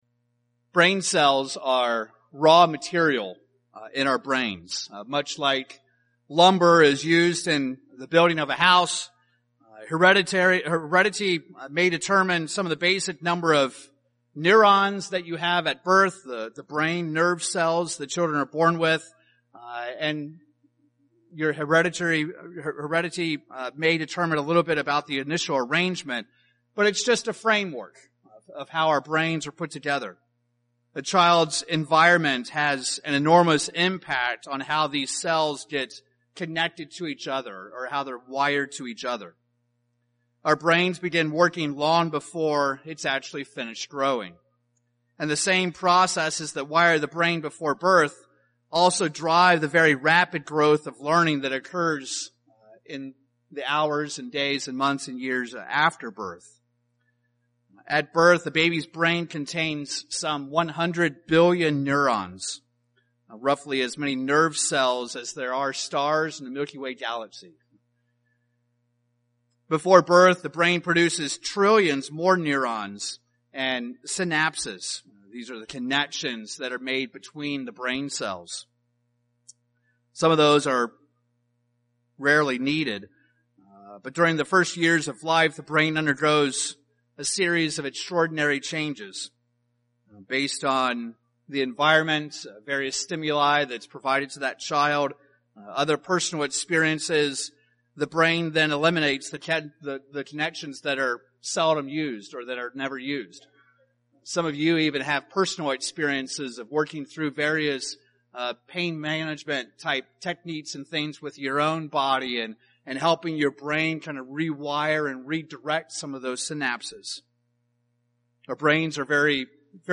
Like a newborn baby grows up into an adult, our Father expects us to grow spiritually and fully put on the mindset of the God family. In this sermon, we'll examine two primary ways we can grow into an unleavened you.